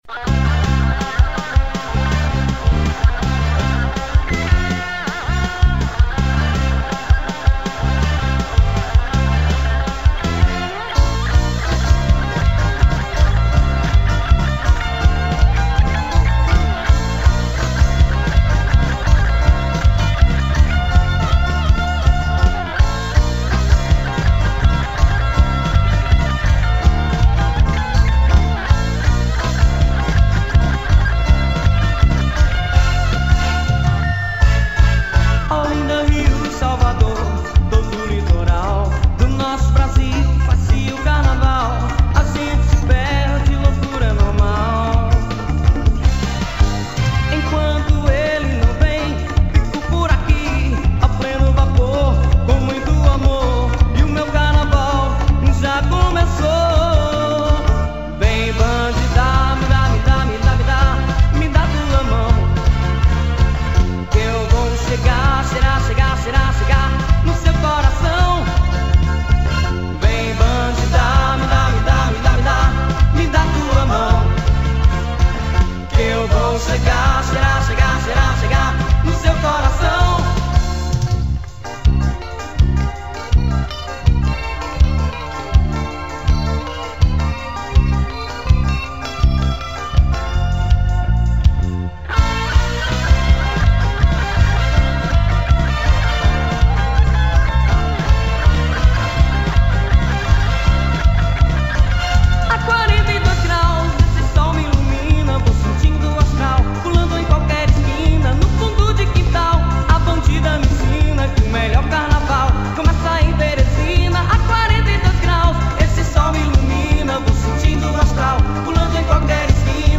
Teclados
Guitarra
Voz